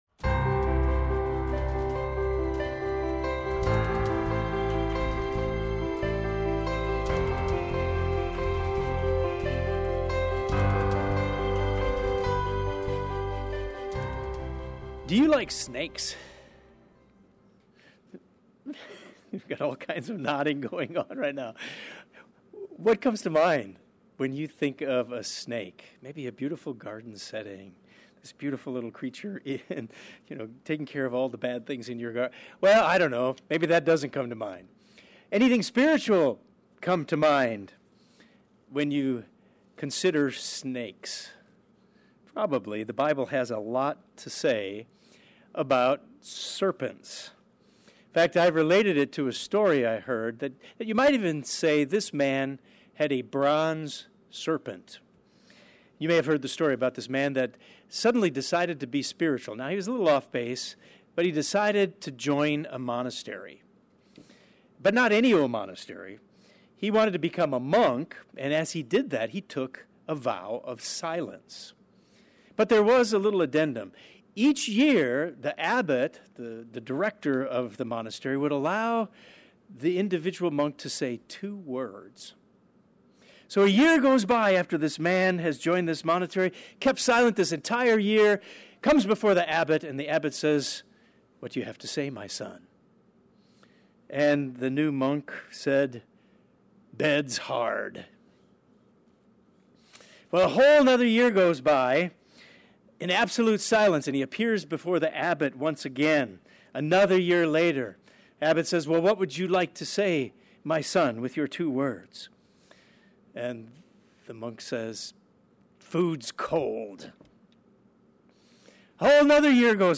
This sermon discusses the great symbolism of the bronze snake and its importance to us.